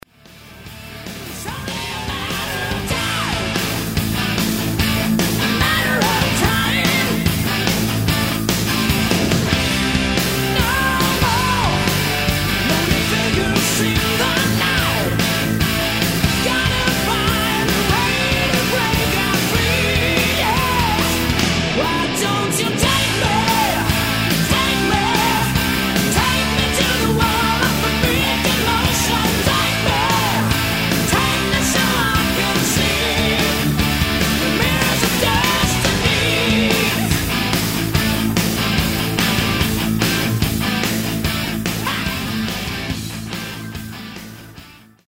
Swiss Hard Rock band
For pure hard driven rock and roll